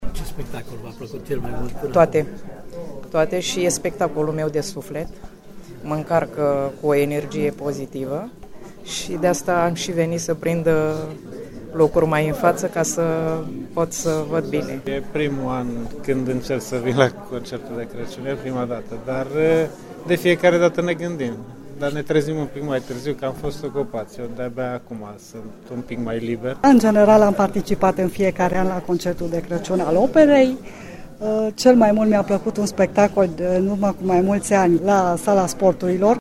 gala-voxuri.mp3